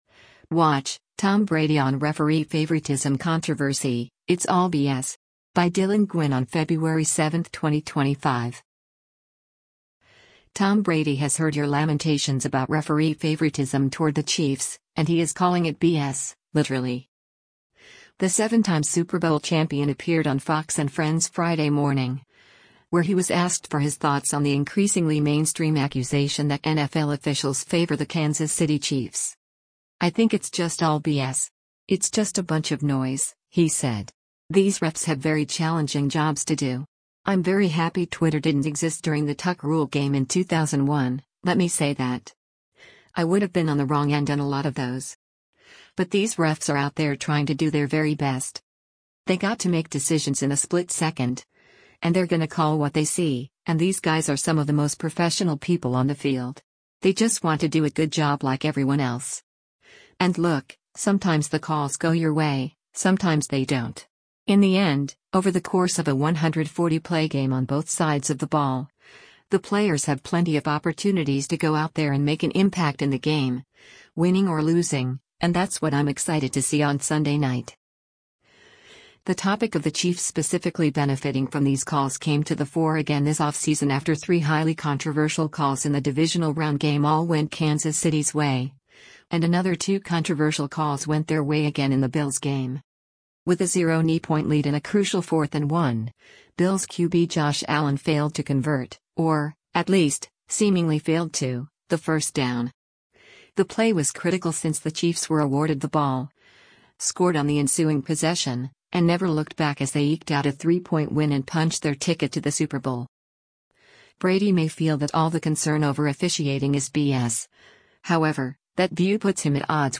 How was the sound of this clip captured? The 7-time Super Bowl champion appeared on Fox & Friends Friday morning, where he was asked for his thoughts on the increasingly mainstream accusation that NFL officials favor the Kansas City Chiefs.